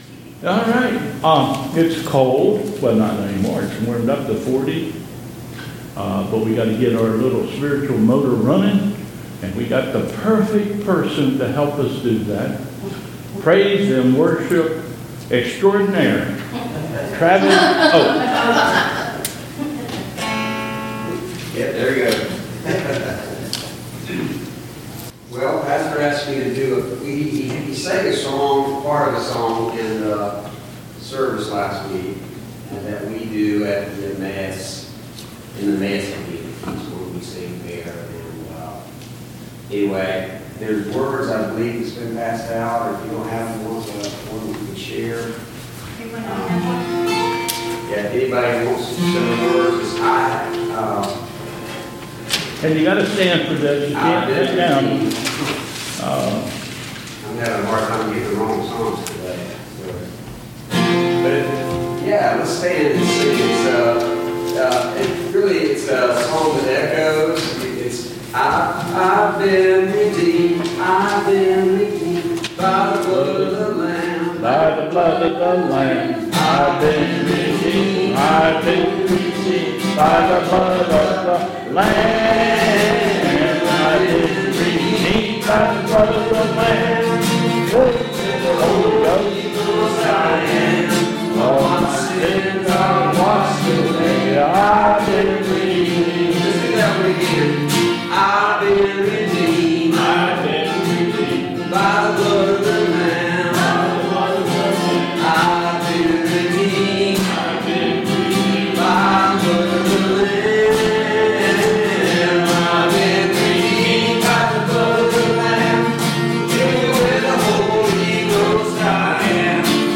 Congregational Praise Song